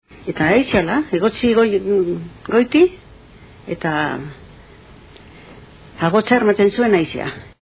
Dialectos
Salacenco